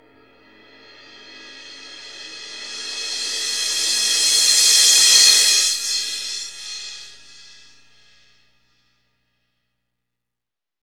Index of /90_sSampleCDs/Roland LCDP03 Orchestral Perc/CYM_Cymbal FX/CYM_Stick Rolls
CYM CRES 04R.wav